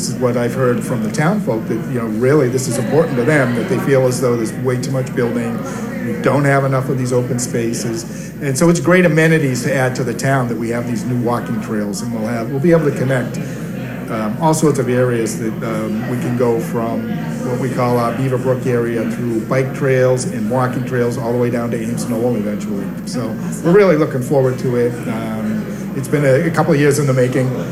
Town Manager, Scott Lambiase, says that because this article passed, the town is able to preserve natural resources.